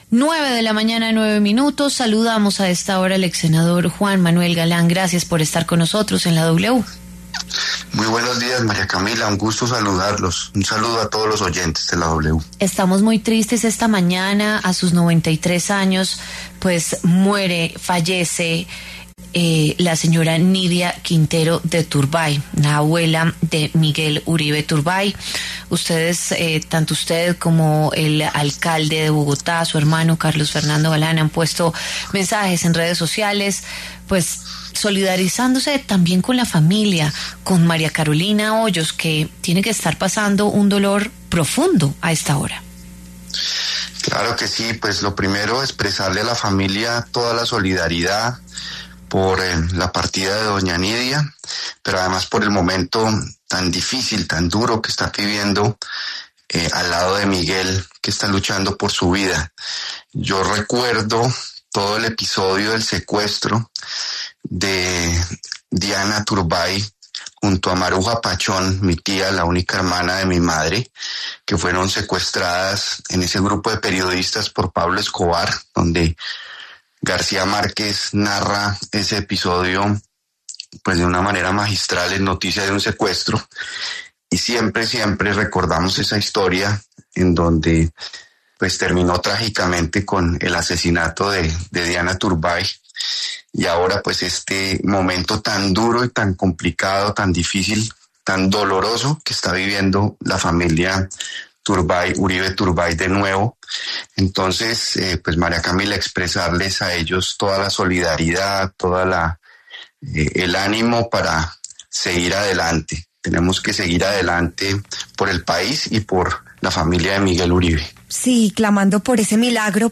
El exsenador habló con La W sobre el supuesto plan “golpista” del excanciller Álvaro Leyva contra el Gobierno Petro.